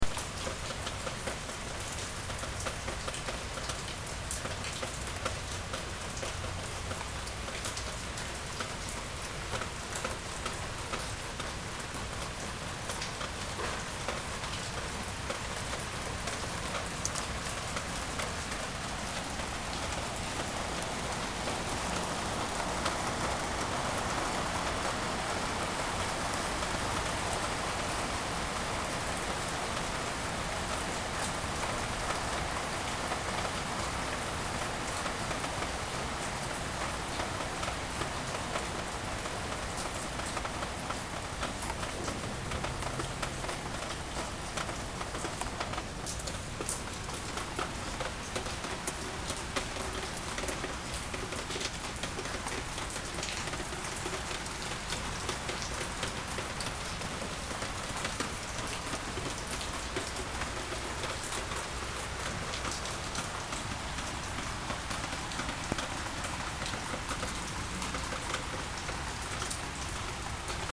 雨の音 MP3 無料ダウンロード
トップページ Blog 雨の音 MP3 無料ダウンロード 雨の音 MP3 無料ダウンロード Tweet 雨がたくさん降るので雨音を録音してみました。
(MP3になっています) 屋根に当たった音が聞こえてきます。